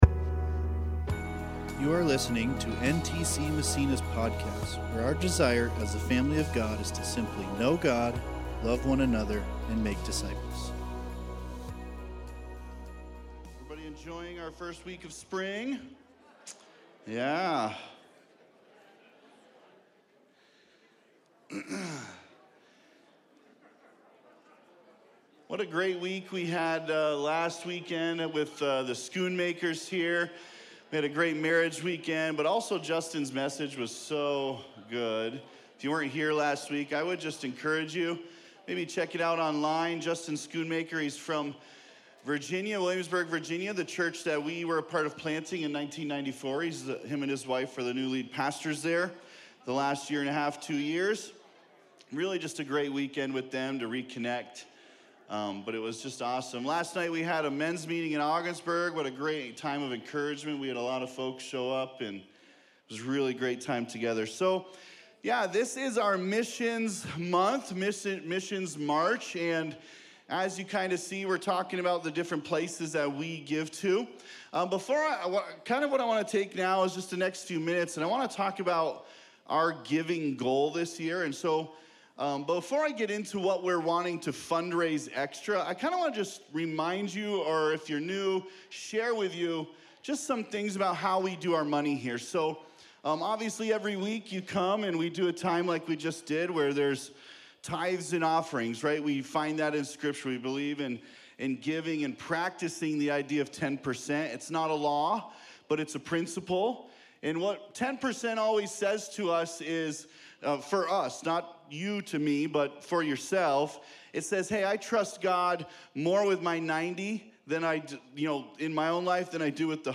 This Sunday at NTC!